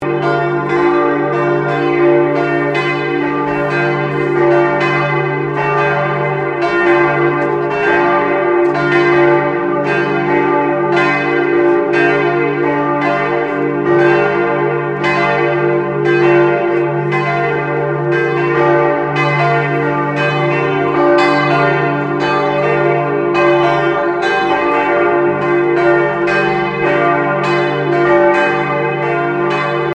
Die Glocken
Pfarrer und Gemeindevorstände hatten für die vier neuen Glocken die aus Kupferzinnbronze in der Gießerei der Gebrüder Bachert in Karlsruhe gegossen wurden eifrig Spenden gesammelt.
Glocke 5 -1500 kg. Ton d. Inschrift: Ich lebe und ihr sollt auch leben.
Hier können Sie das Glockengeläut hören.
Glockengeläut_Luther.mp3